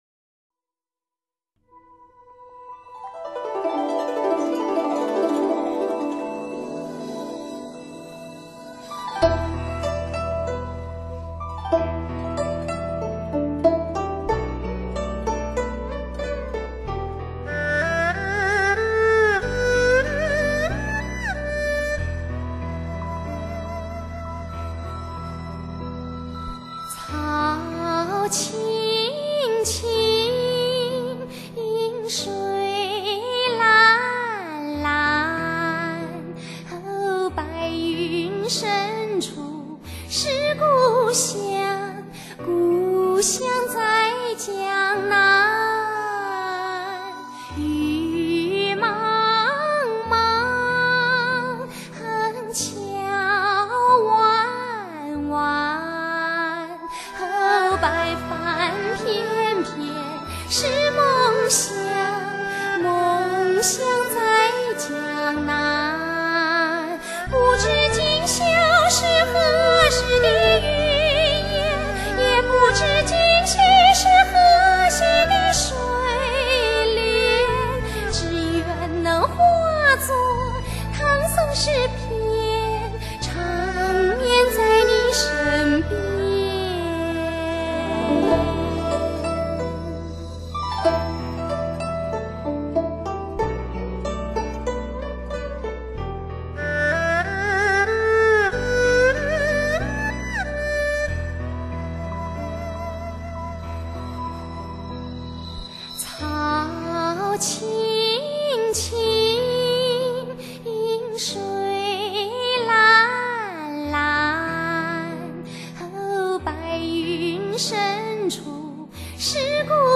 柔美、恬静、妩媚
清爽、舒适、怡人